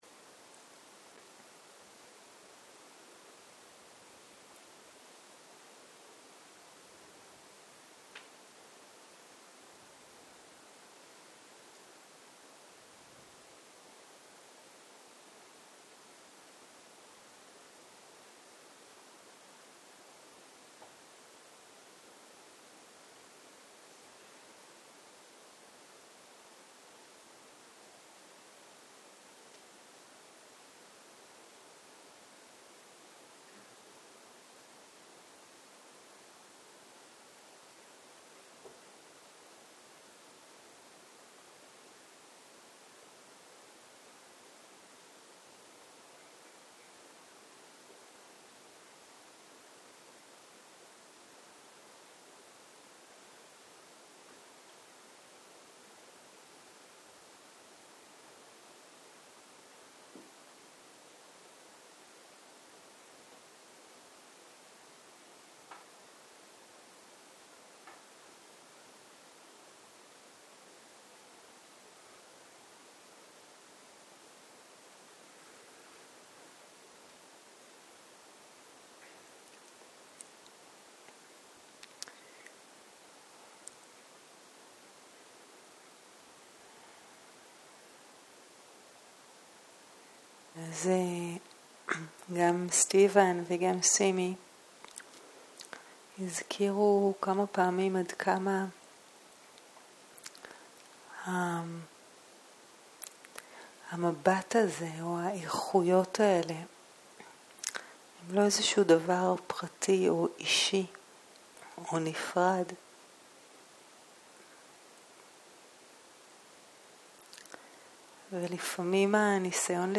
ערב - מדיטציה מונחית
סוג ההקלטה: מדיטציה מונחית